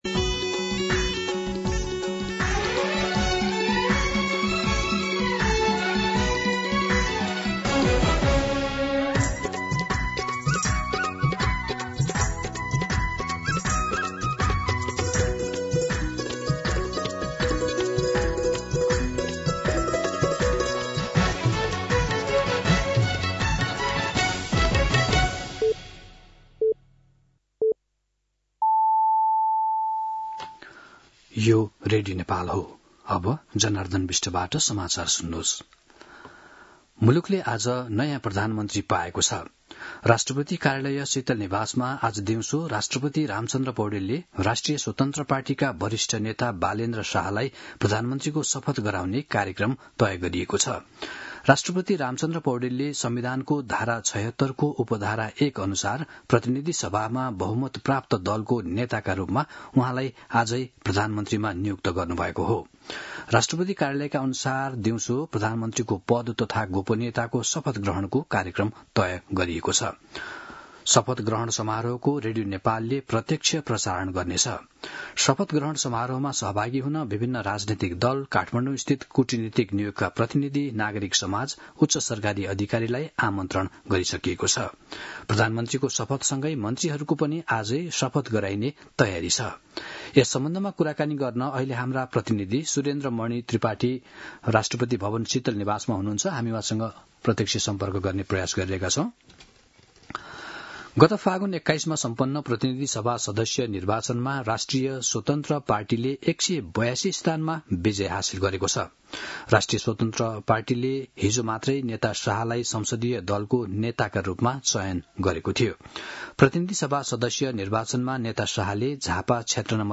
मध्यान्ह १२ बजेको नेपाली समाचार : १३ चैत , २०८२